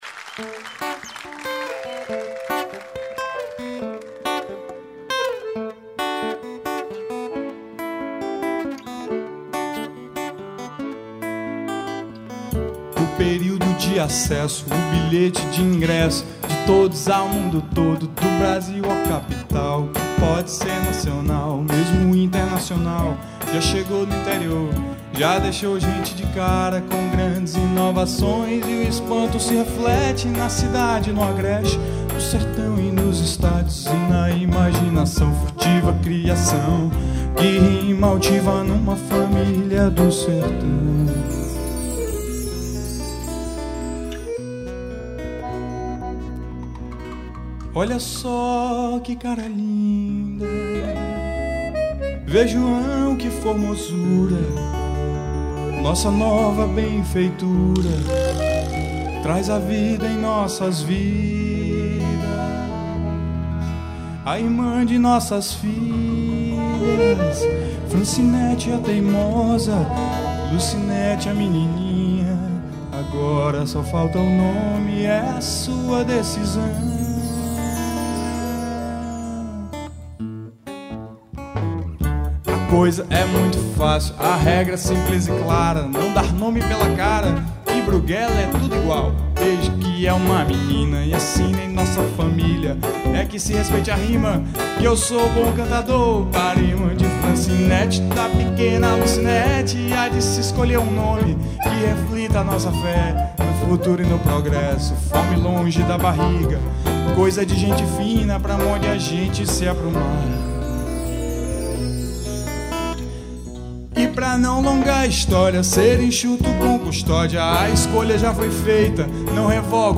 2846   02:49:00   Faixa: 4    Mpb